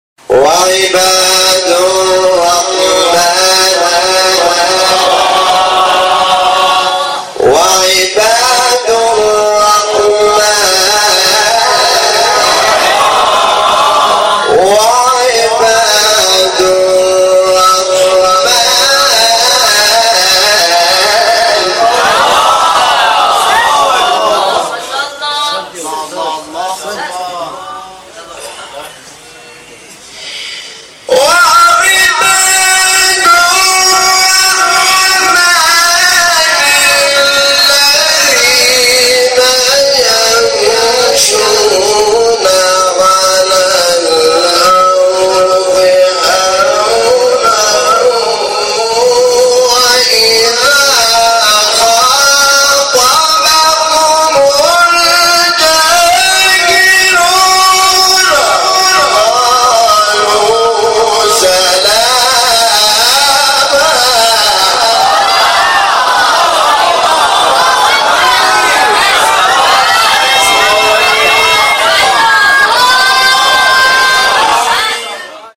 سوره : فرقان آیه : 63 استاد : حامد شاکرنژاد مقام : رست قبلی بعدی